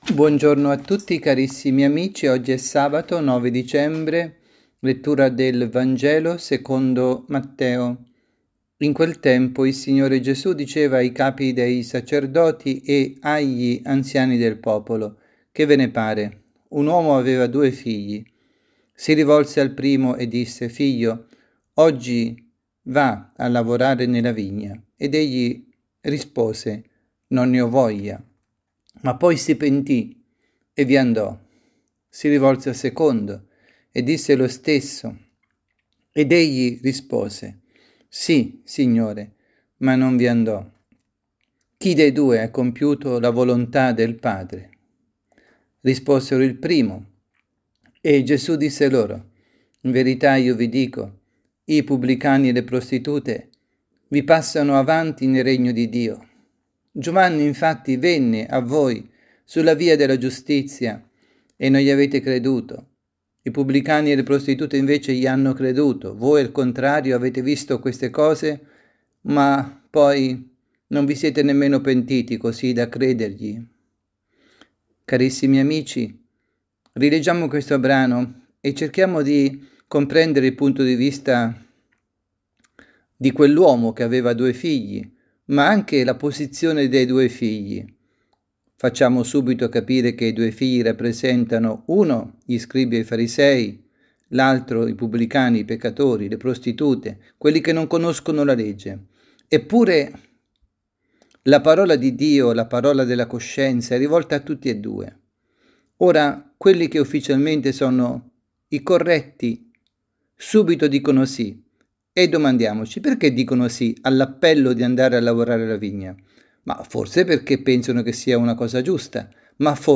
Avvento, avvisi, Omelie